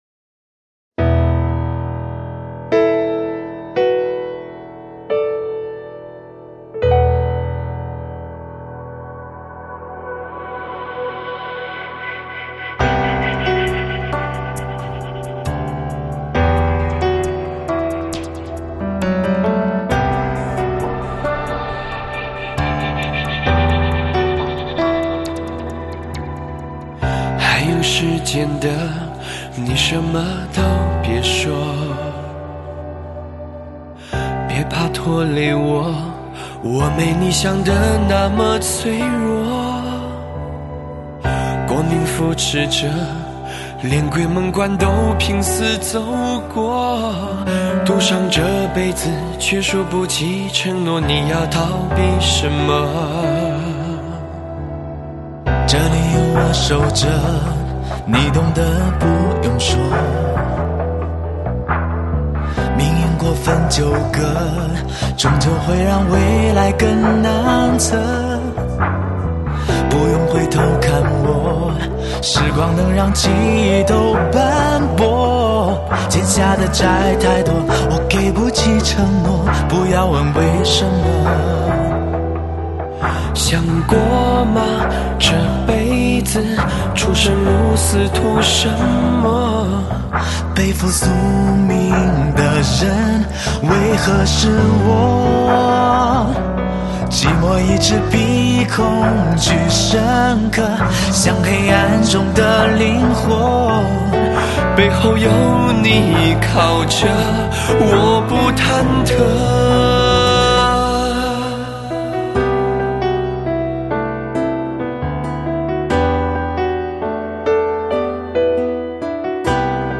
古风歌曲 你是第7412个围观者 0条评论 供稿者： 标签：,